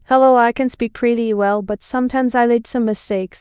OuteAI / OuteTTS-0.1-350M like 99 Follow OuteAI 50